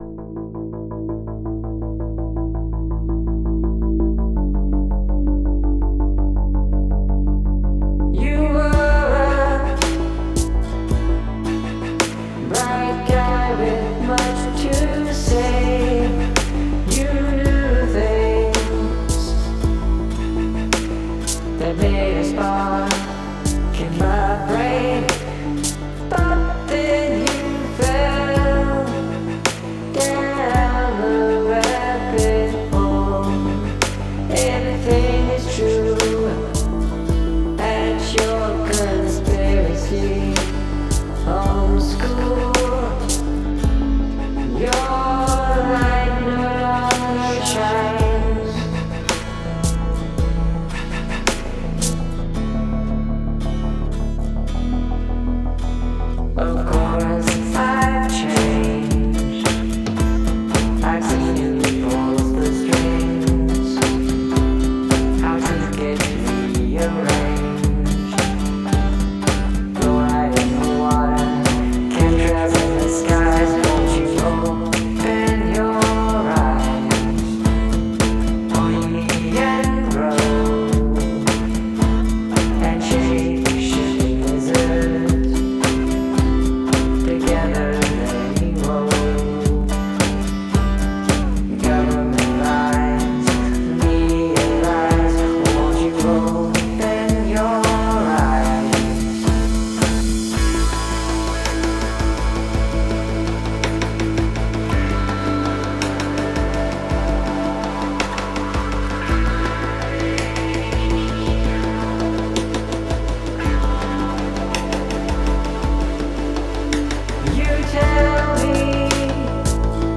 Multiple Narrators